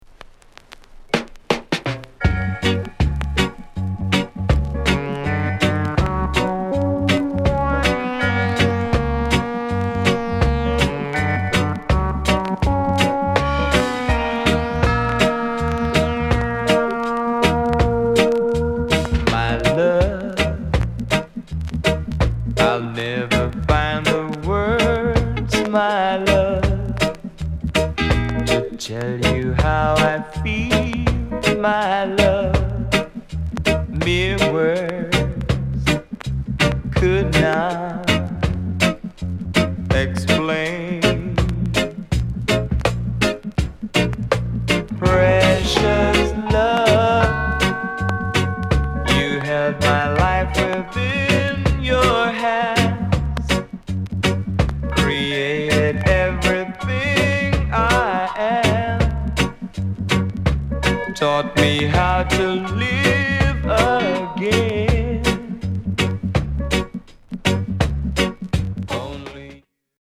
SOUND CONDITION VG
NICE SOUL COVER